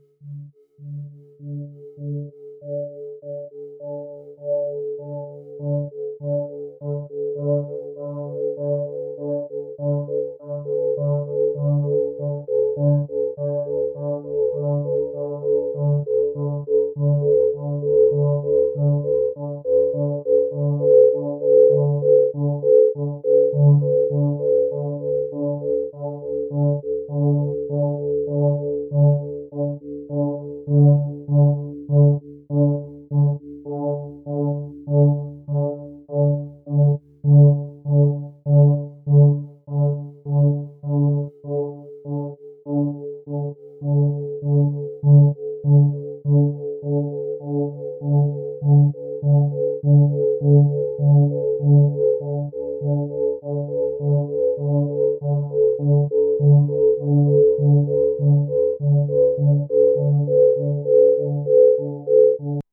III. Bass Convolved with Self (as used in Temple)
Spatialized versions
Each track is rotated (CW or CCW) with the automated diffusion control offered in the ABControl software for the AudioBox, to a sequence of output channels at a rapid pace (100, 150 or 200 ms per step), with no cross-fade.